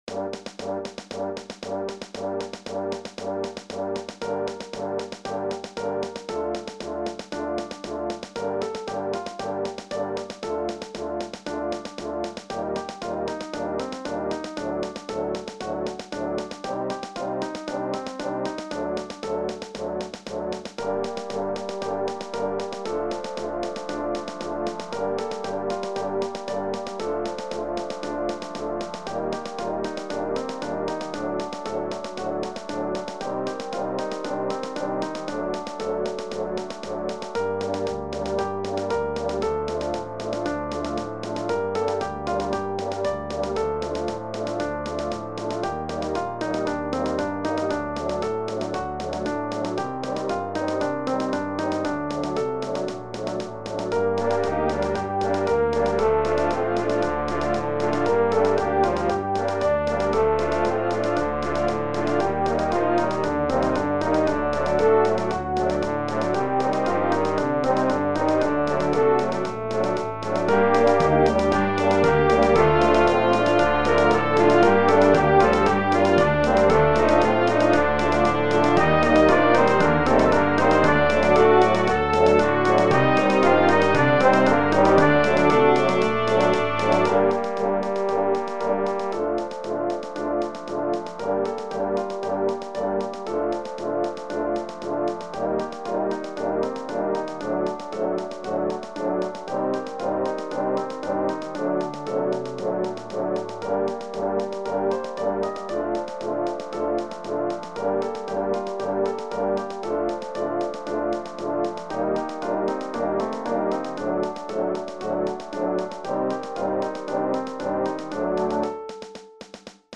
Russische traditional
Bezetting : Brassband